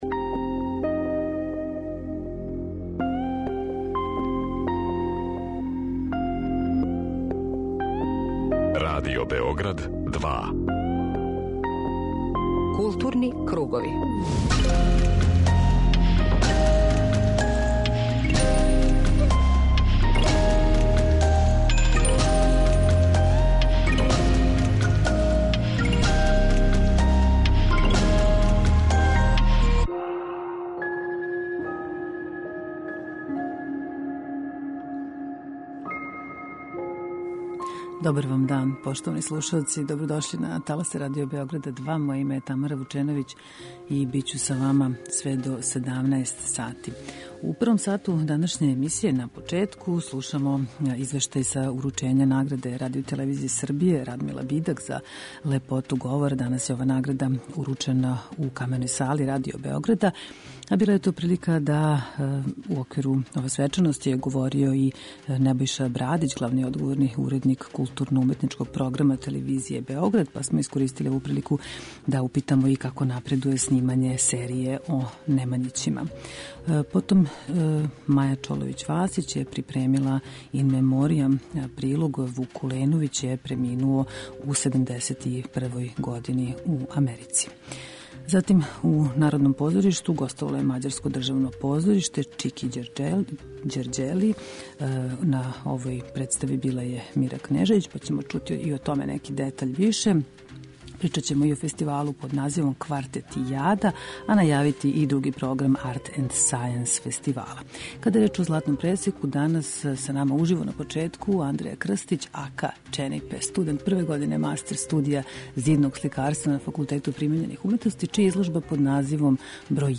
Са нама уживо